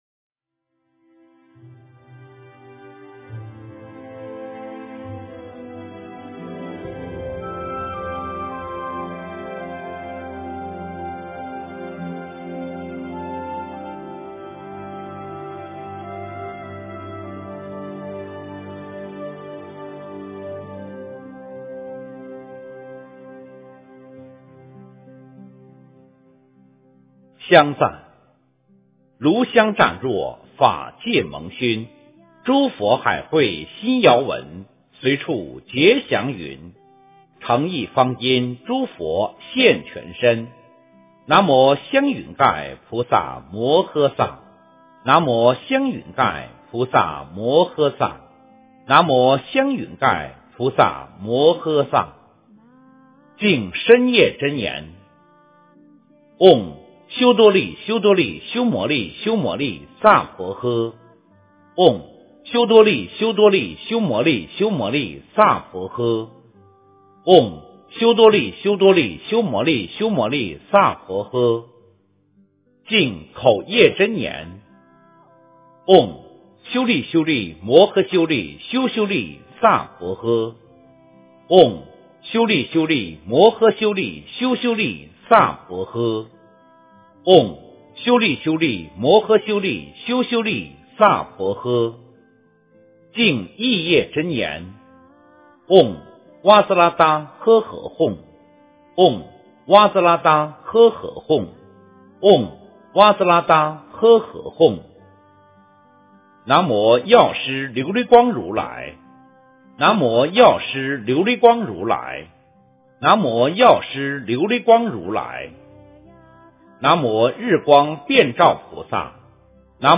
药师琉璃光七佛本愿功德经 - 诵经 - 云佛论坛